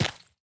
footsteps
ore-07.ogg